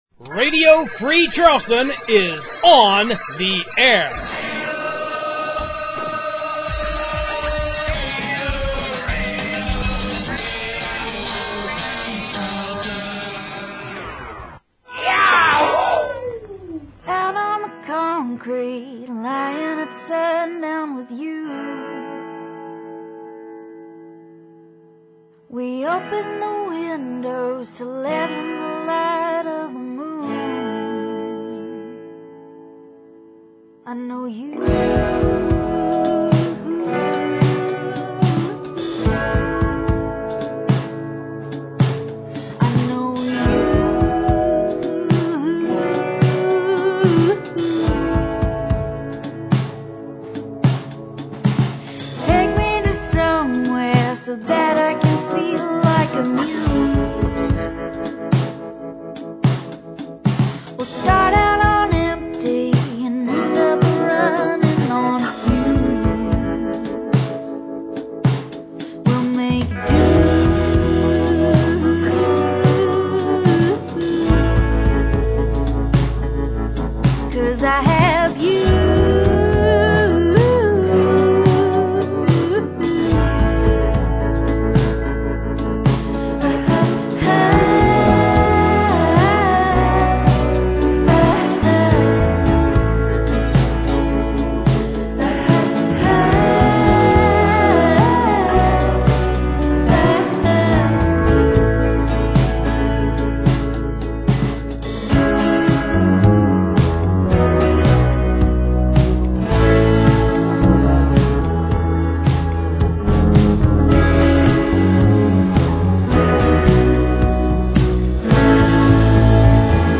Radio Free Charleston brings you a new show with three full hours of newly-assembled music. Our first two hours are our usual mix of great local, independent and cult music, and our third hour is a mixtape of covers, some of which are goofy as hell.